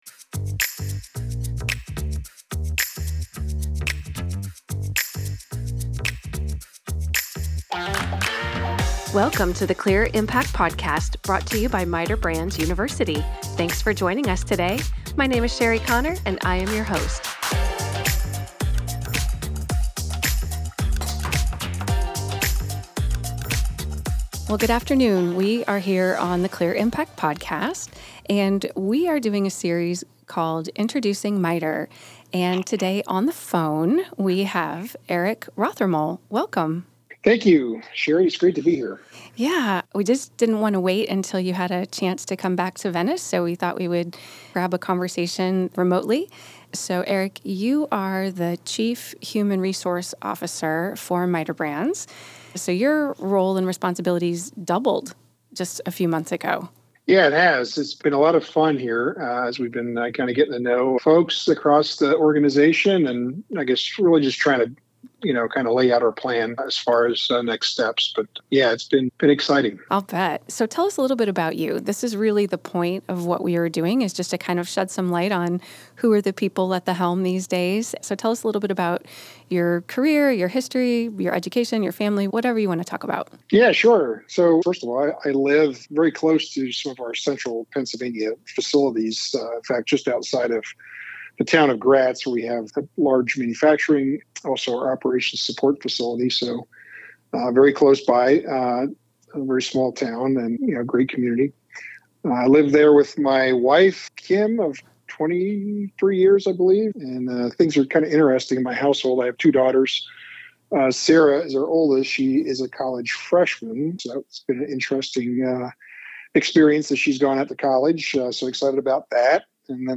It's not a big transition when the existing cultures are so closely aligned regarding: team member well being, shared commitments to excellence and safety, and giving back to our communities. This conversation verifies that our focus really is on the people.